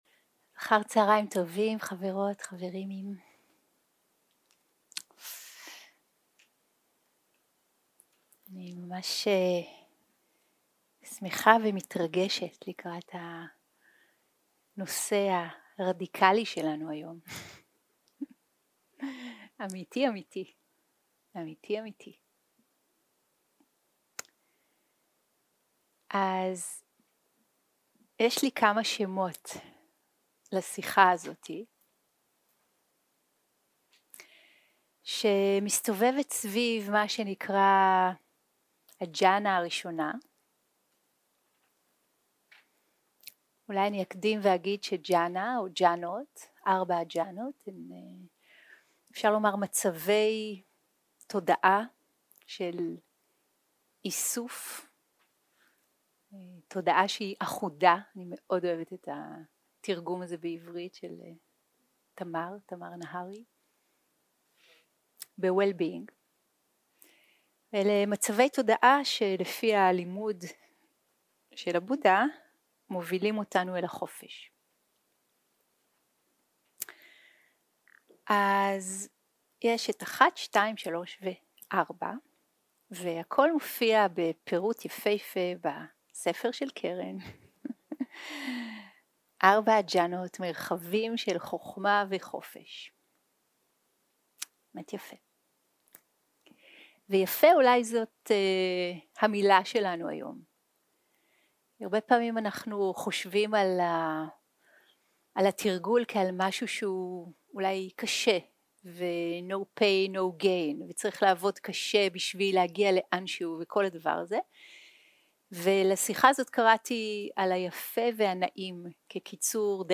הקלטה 7 - יום 3 - אחהצ - שיחת דהרמה - אושר ועונג - הג'האנה הראשונה 0:00 0:00 סוג ההקלטה: Dharma type: Dharma Talks שפת ההקלטה: Dharma talk language: Hebrew